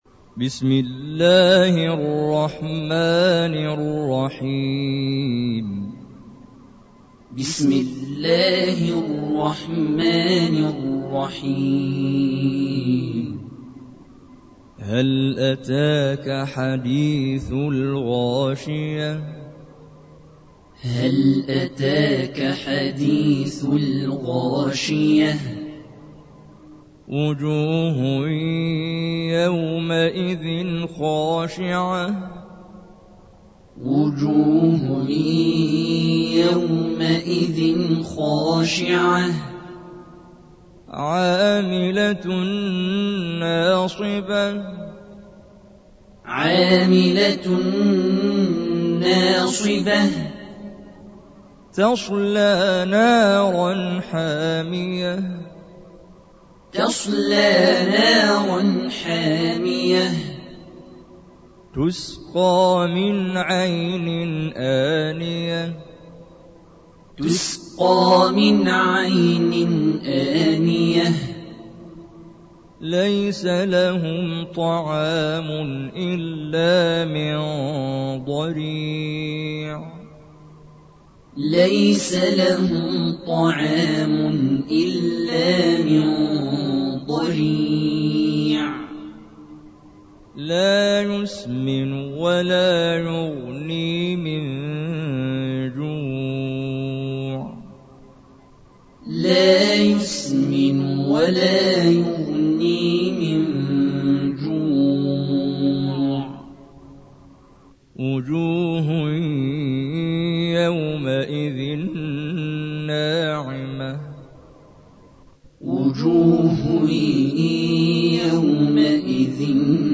Surah Repeating تكرار السورة Download Surah حمّل السورة Reciting Muallamah Tutorial Audio for 88. Surah Al-Gh�shiyah سورة الغاشية N.B *Surah Includes Al-Basmalah Reciters Sequents تتابع التلاوات Reciters Repeats تكرار التلاوات